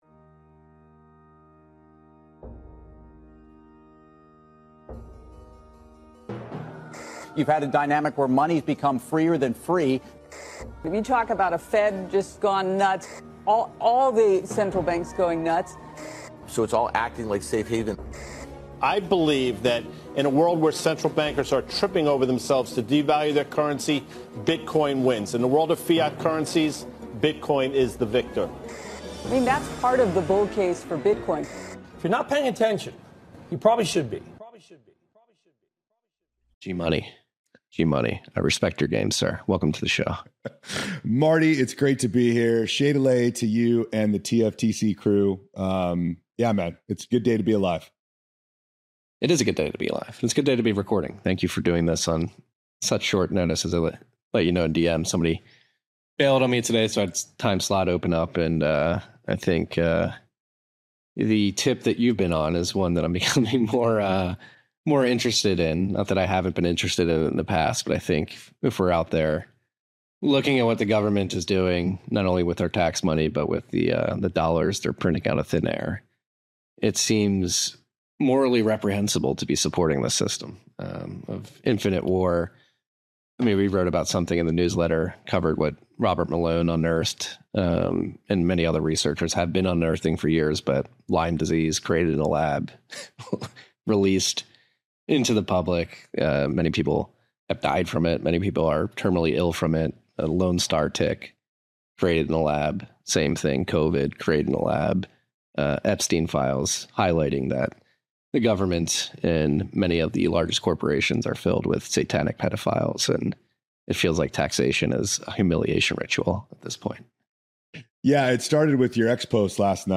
Swan Signal Live brings you interviews, news, analysis, and commentary on Bitcoin, macro, finance, and how Bitcoin is the foundation for a brighter future for us all.